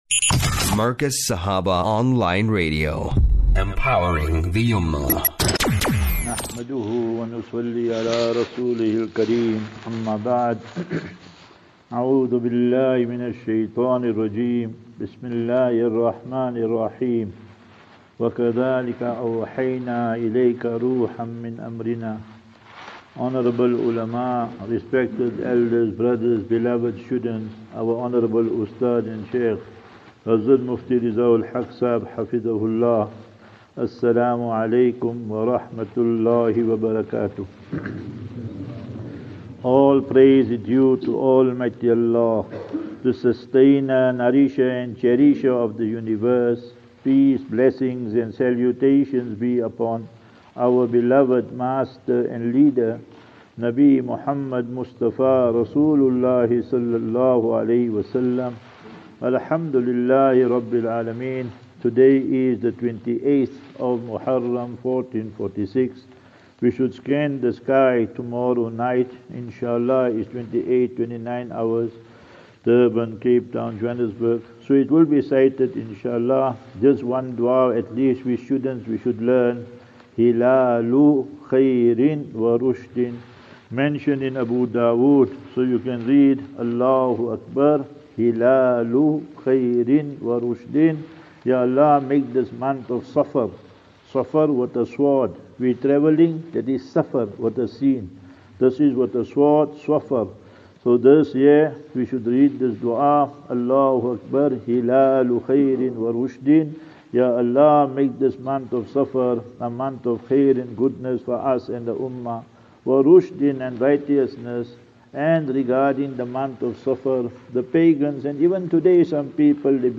Lectures